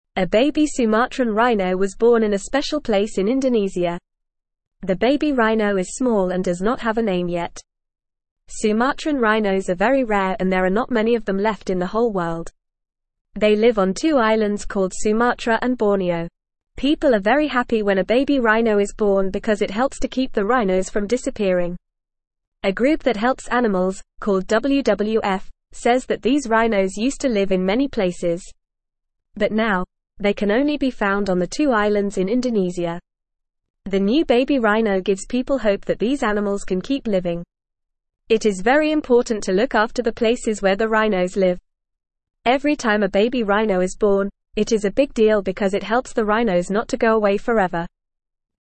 English-Newsroom-Beginner-FAST-Reading-Baby-Sumatran-Rhino-Brings-Hope-for-Rare-Animals.mp3